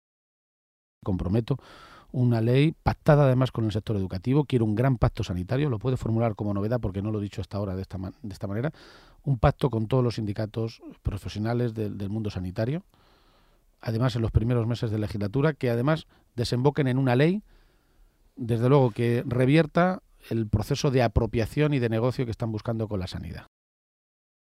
Cortes de audio de la rueda de prensa
Audio Page-entrevista OCR 2